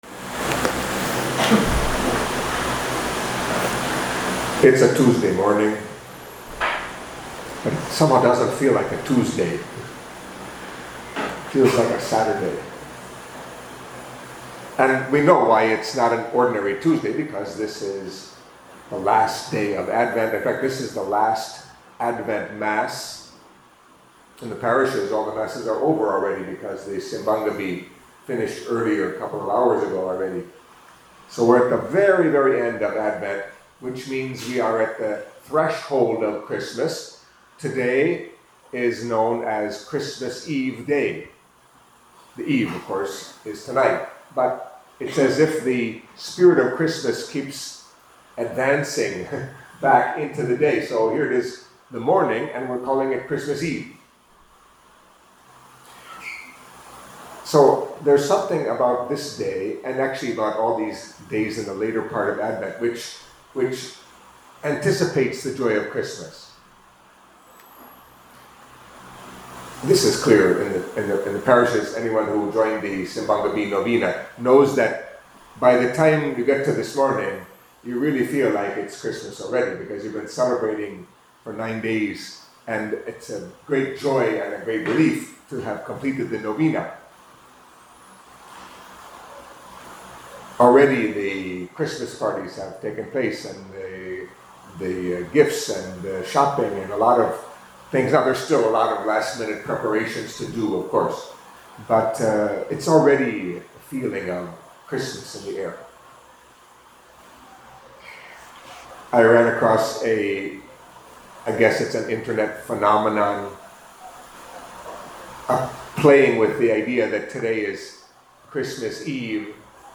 Catholic Mass homily for Tuesday of the Fourth Week of Advent